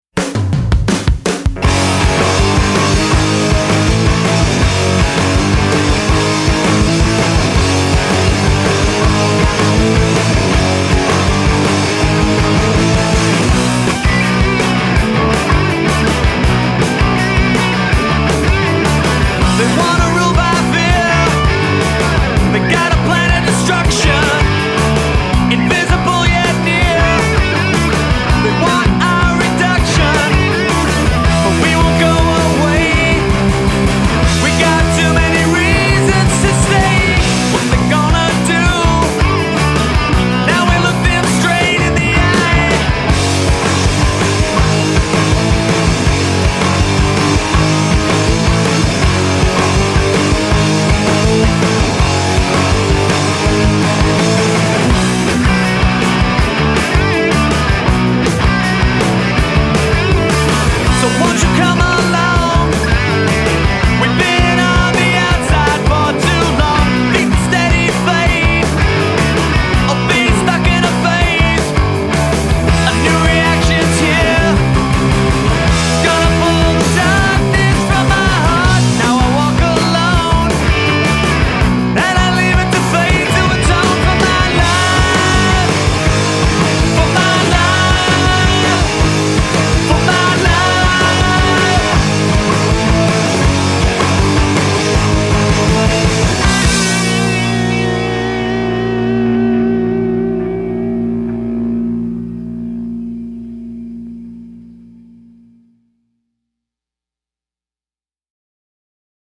vocals; electric and acoustic guitars
bass on all tracks